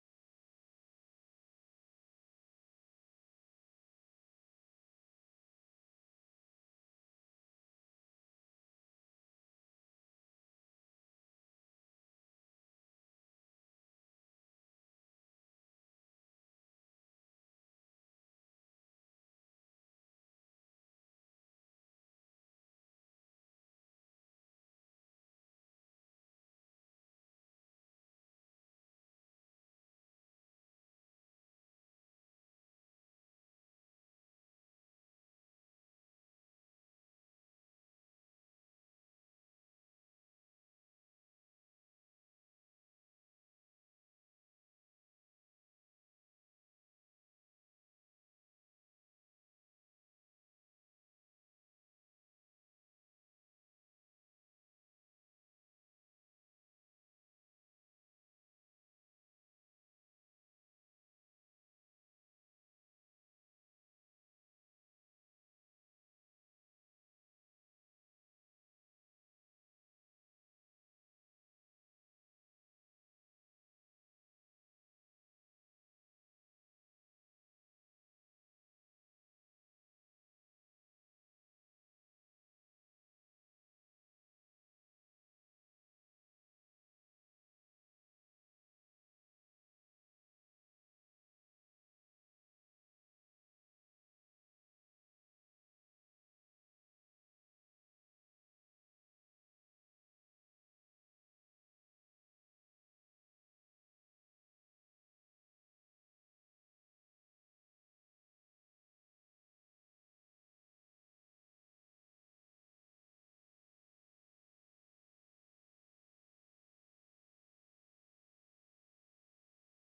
(10 Mar 09-RV) In the US state of Connecticut, the legislature is considering a bill that would alter the corporate structure of Catholic dioceses and parishes by requiring all parishes and diocese to have lay-majority boards of trustees on which local bishops would be non-voting members We spoke with the bishop of Bridgeport, Ct., William Lori…